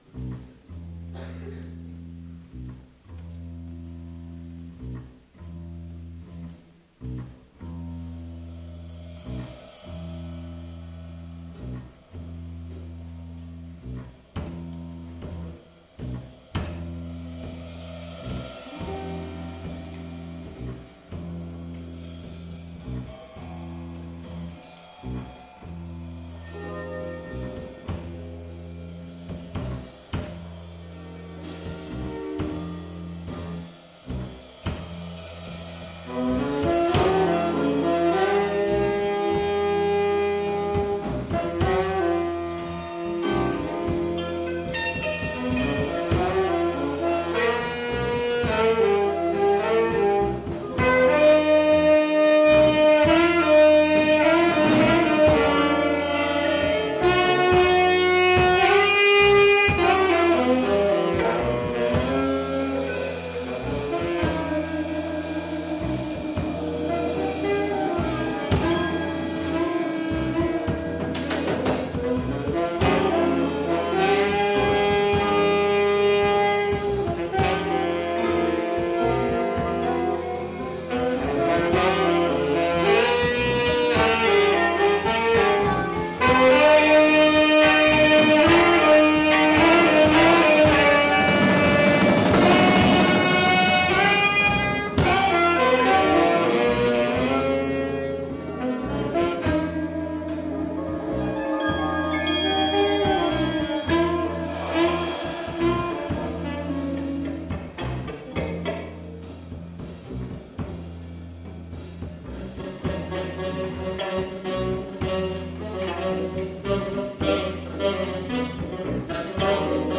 · Genre (Stil): Jazz
· Kanal-Modus: stereo · Kommentar